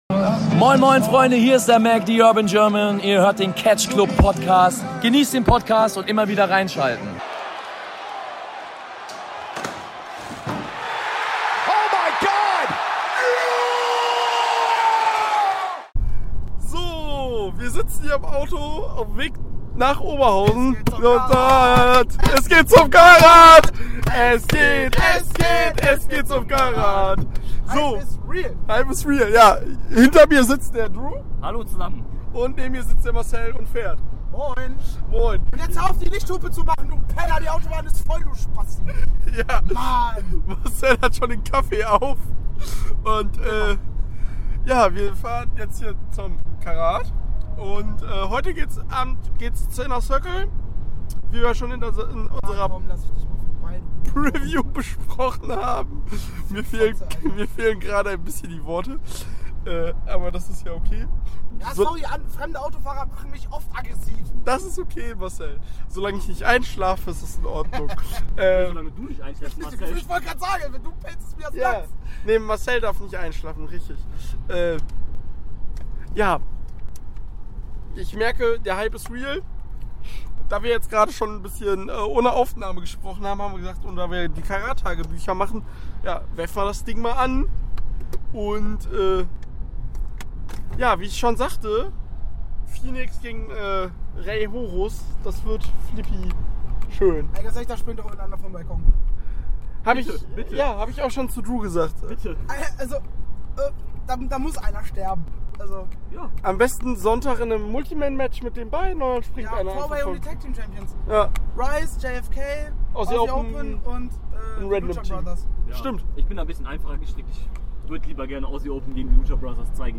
Aus diesem Grund gibt es ein Carat Tagebuch von uns. Das erste Tagebuch wurde während der Anreise und nach Inner Circle aufgenommen.